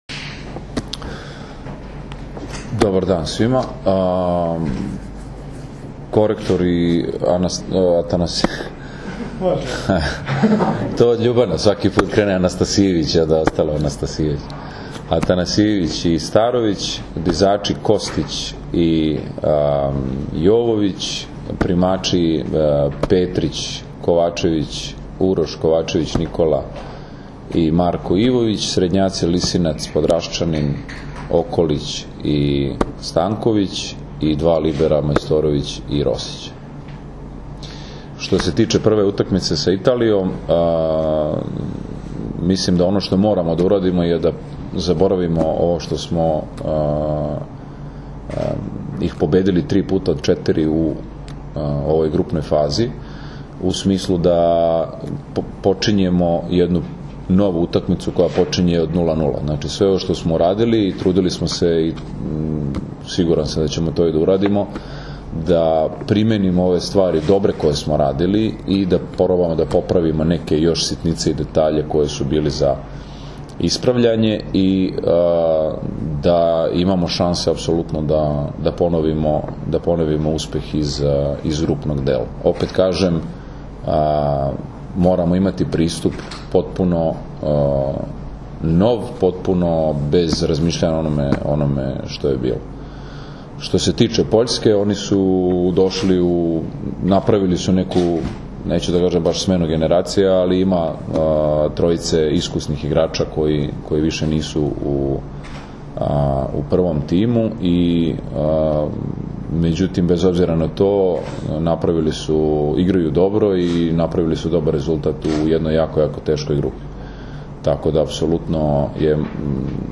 IZJAVA NIKOLE GRBIĆA